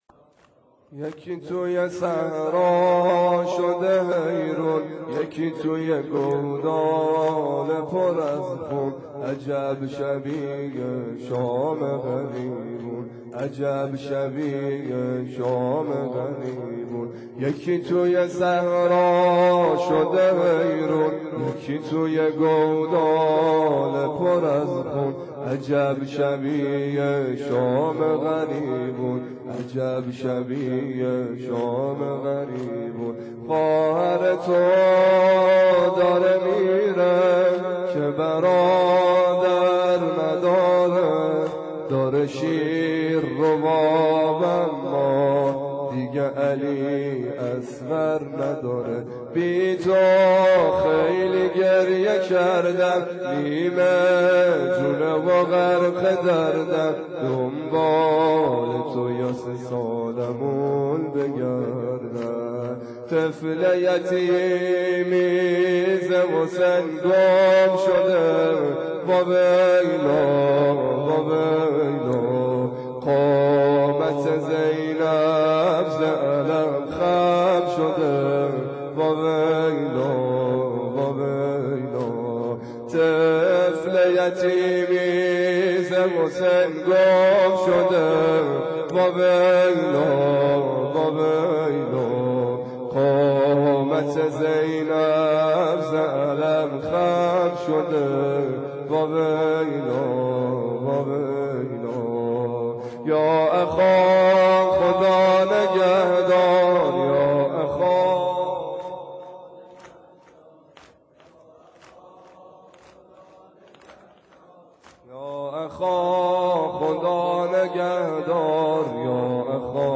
شب یازدهم _ زمینه